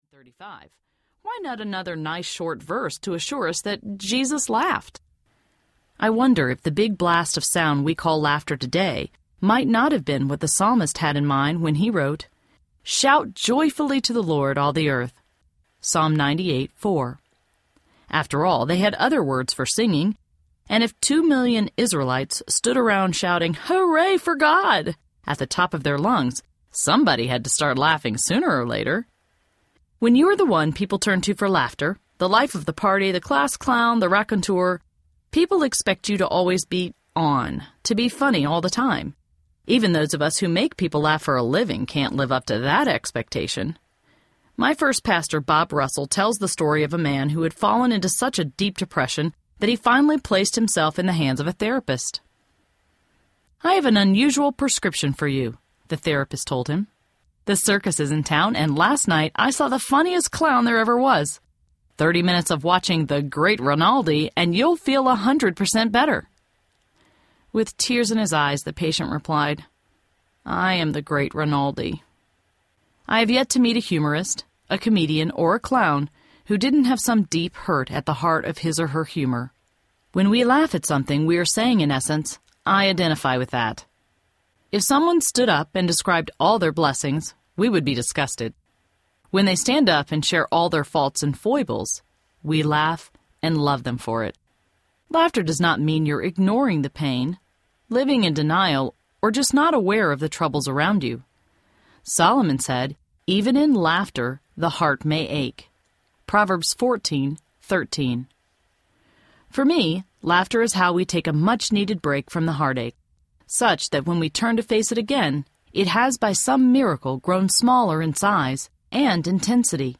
She Who Laughs, Lasts! Audiobook
Narrator
5.7 Hrs. – Unabridged